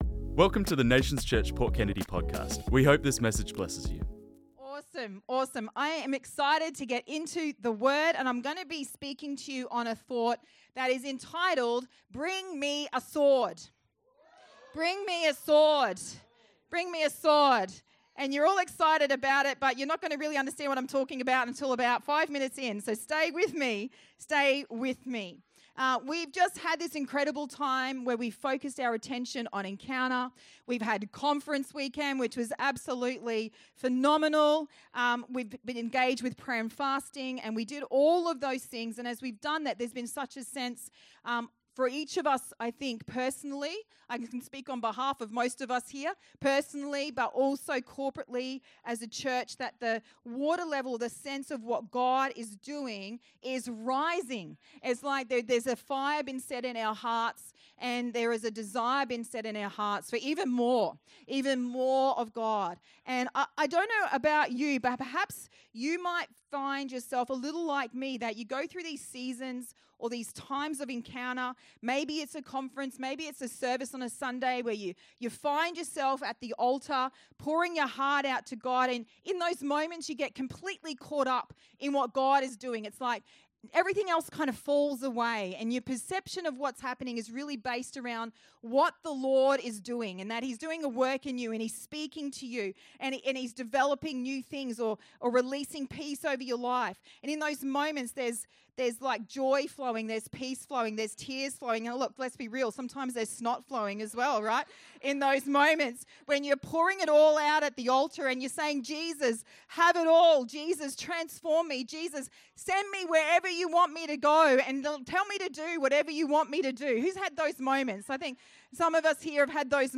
This message was preached on Sunday the 27th July 2025.